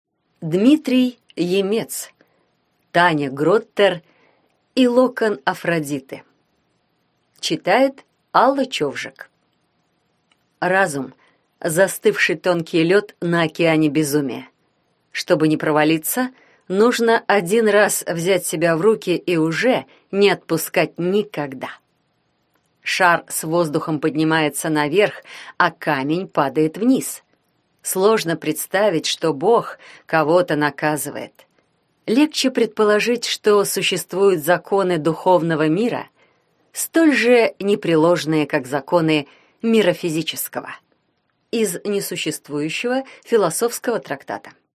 Аудиокнига Таня Гроттер и локон Афродиты | Библиотека аудиокниг